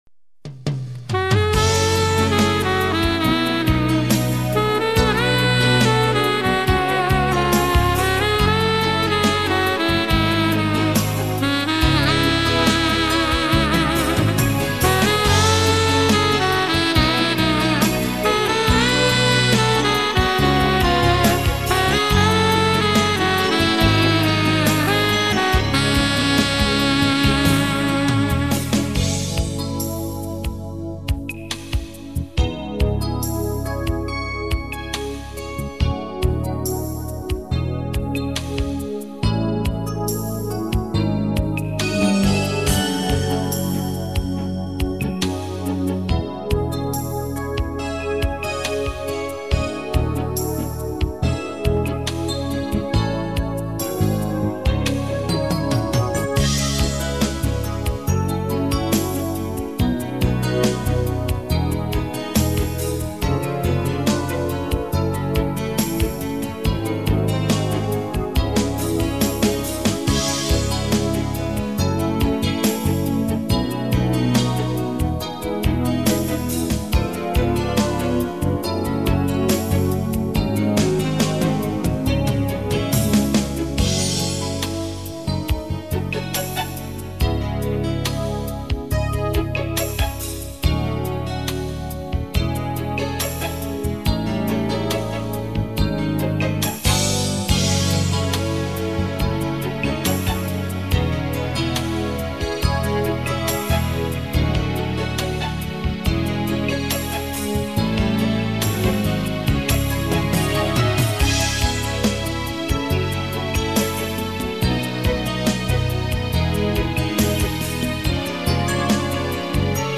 Фонограма-мінус (mp3, 192 kbps)